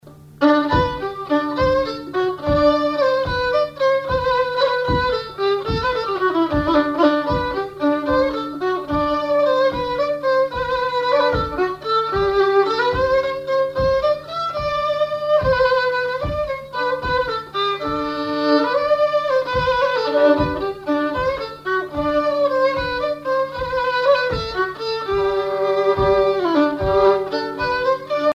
violoneux, violon,
valse musette
Pièce musicale inédite